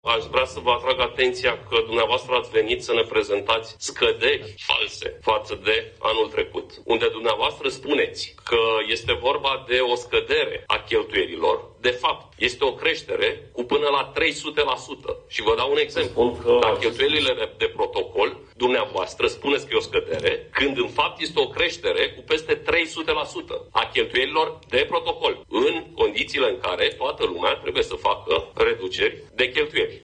Președintele Comisiei pentru IT din Camera Deputaților, Radu Mihaiu: „Ne prezentați scăderi false față de anul trecut”